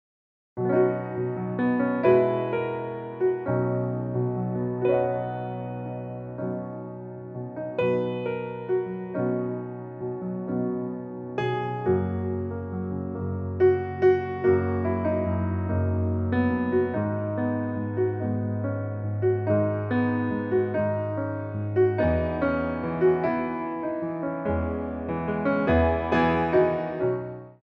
Theme Songs from Musicals for Ballet Class
Piano Arrangements
Slow Tendus
3/4 (16x8)